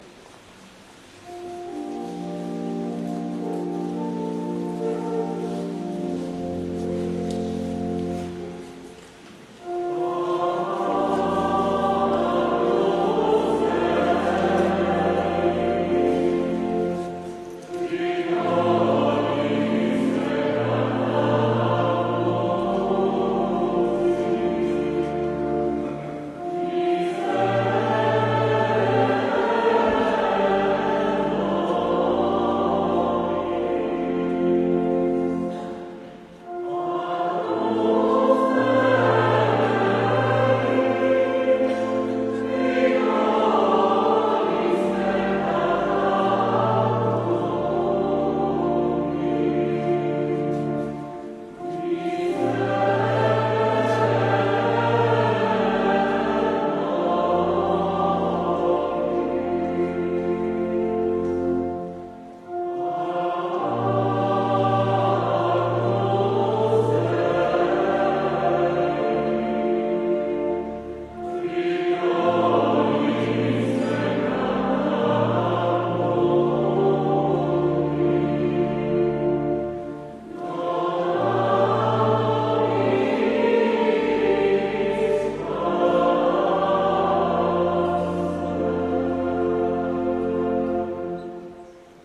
Godz. 10.00 – Msza Św.
oprawa muzyczna chórów parafialnych.